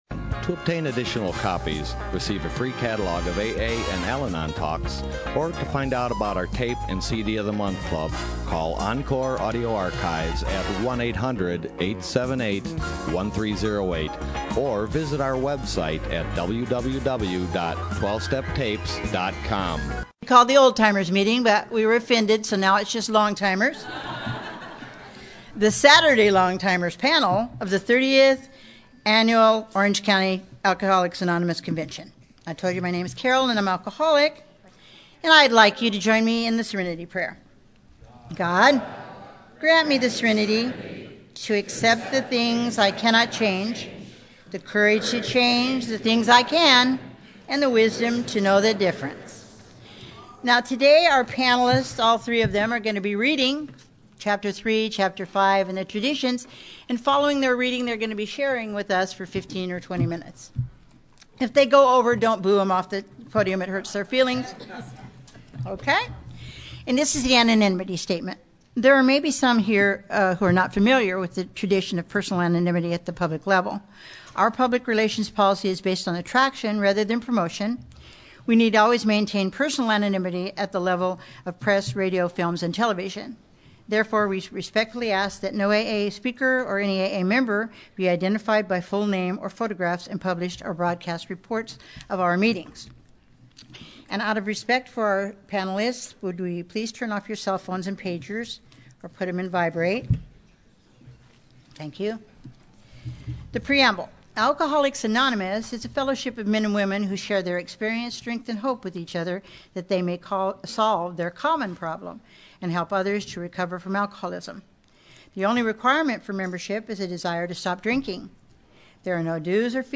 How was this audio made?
Orange County AA Convention 2015 - Long Timers